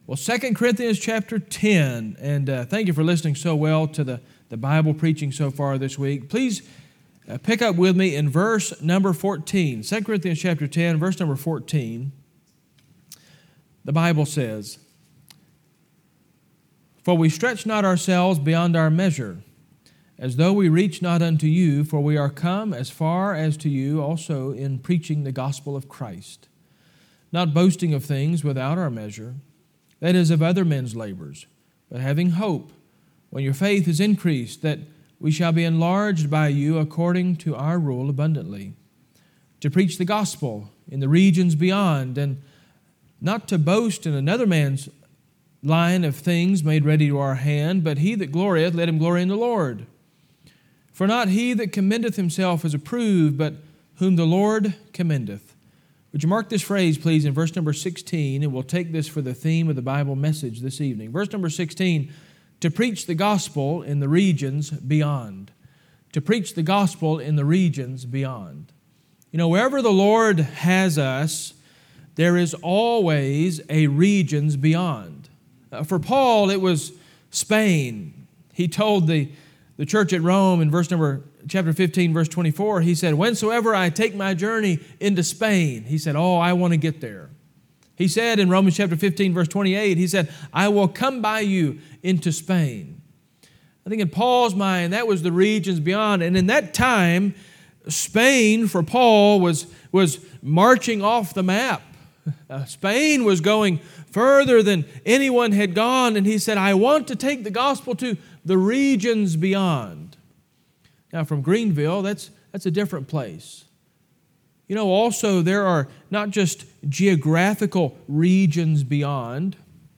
Mission Conference 2025 &middot